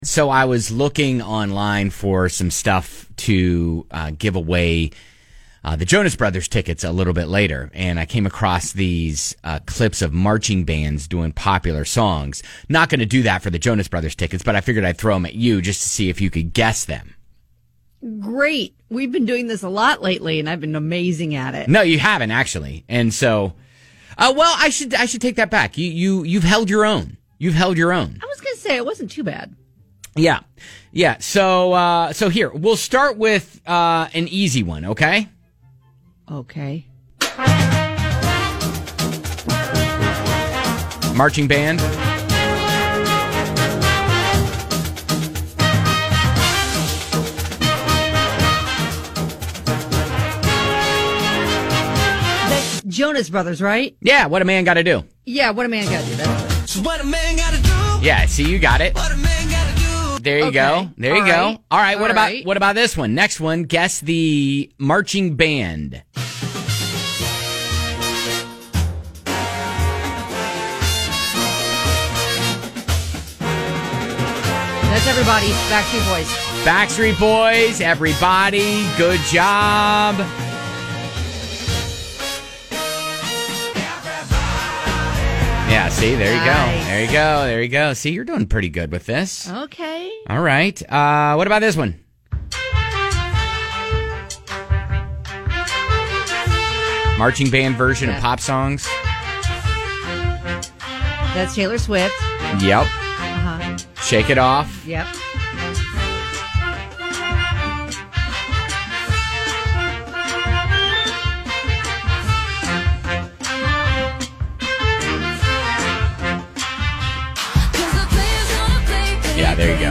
It's so cool when you hear marching bands cover pop songs, isn't it?